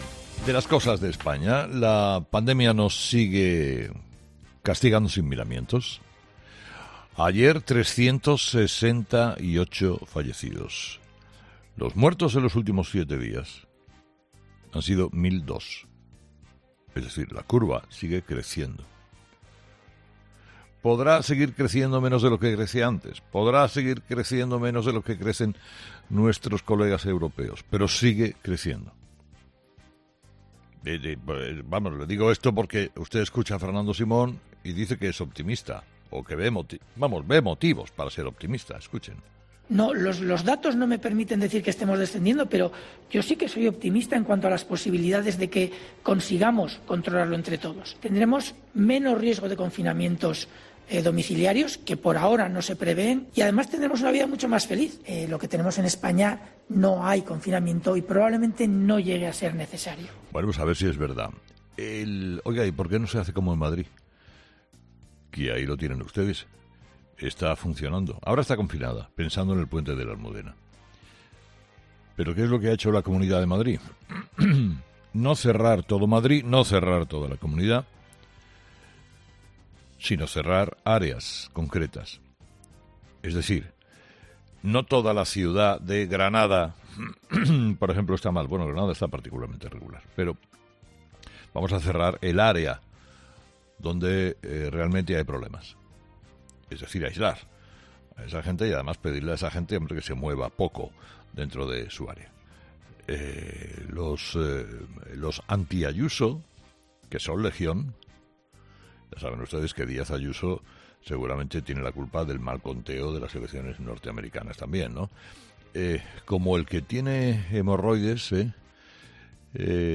El director de 'Herrera en COPE', Carlos Herrera, ha querido comenzar este viernes acercándose a Estados Unidos, que sigue contando votos a un ritmo "caribeño".
"Ahora resulta que sois muy buenos... pero lo cierto es que se os escapó el detalle que en el decreto no exigíais que los siete días tenían que ser consecutivos, Madrid aprovechó esa grieta y ahora estáis recogiendo esas velas como los cuquitos que sois", ha dicho en tono jocoso.